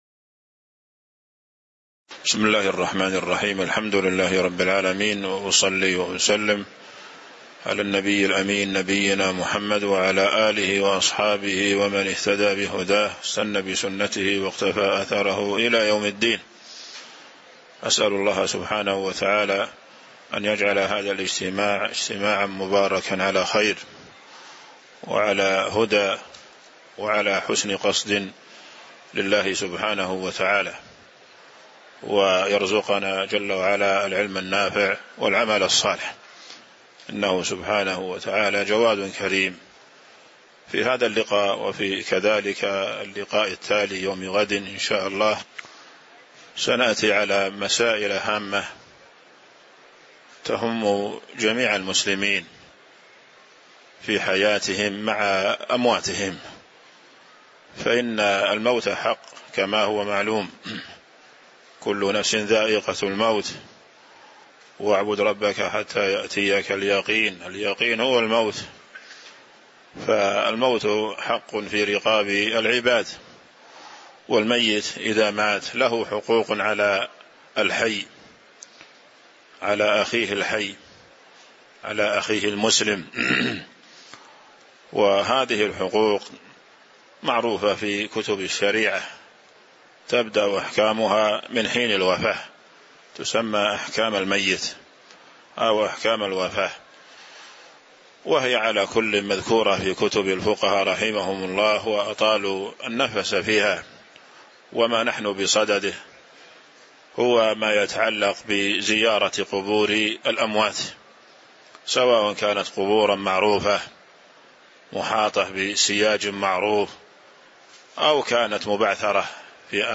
تاريخ النشر ٢٠ محرم ١٤٤٦ هـ المكان: المسجد النبوي الشيخ